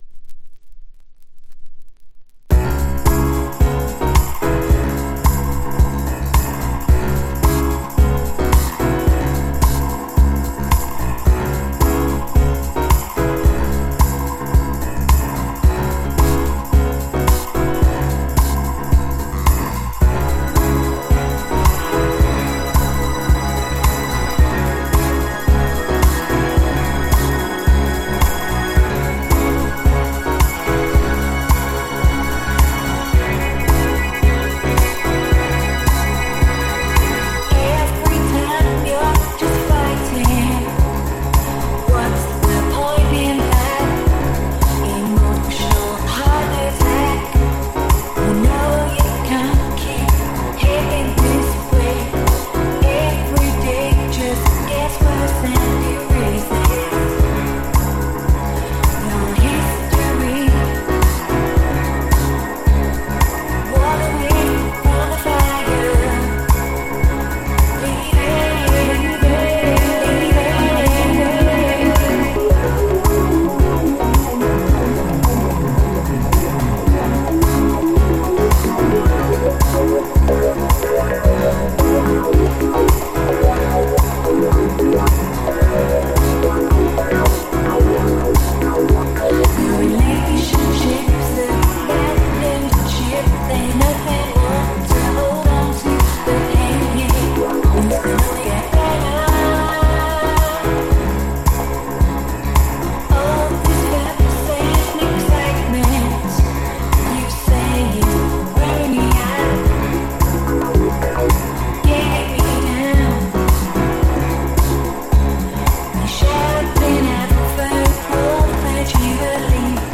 ミニマルなビートにエレピの音。そしてこのフィルターのかかった質感。
House / Techno